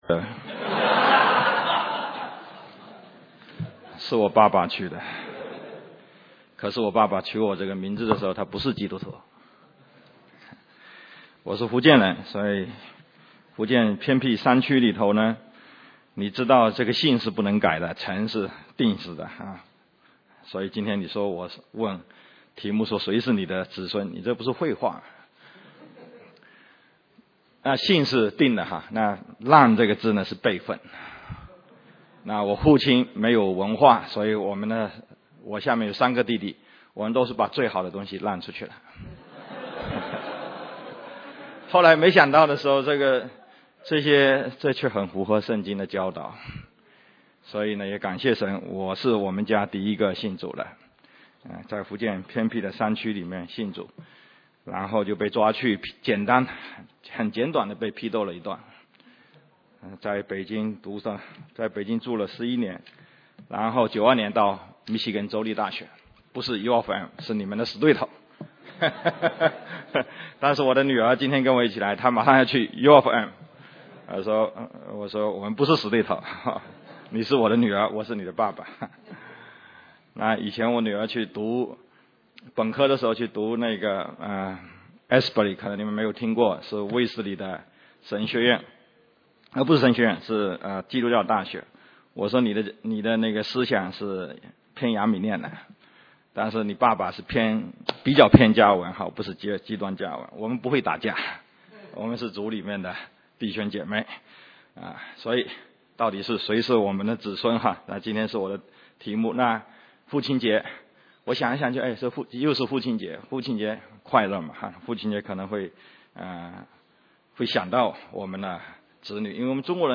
Mandarin Sermons Home / Mandarin Sermons